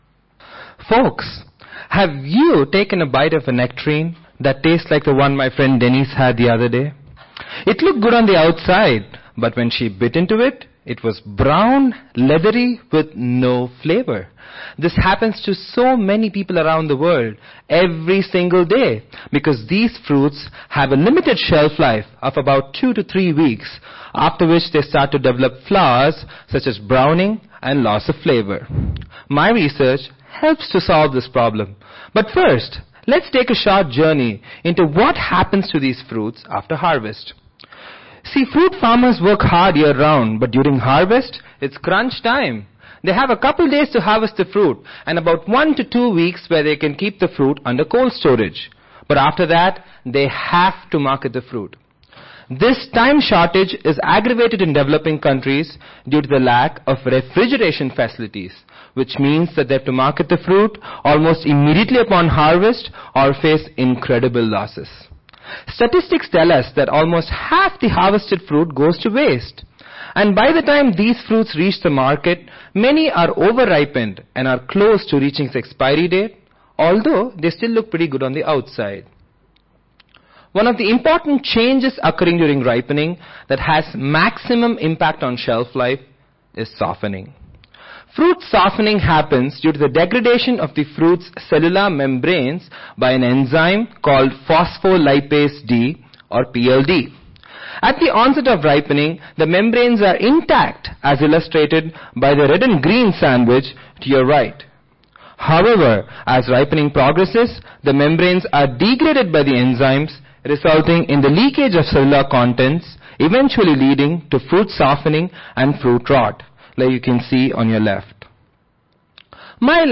Scholars Ignite Competition
Georgetown West (Washington Hilton)
Audio File Recorded Presentation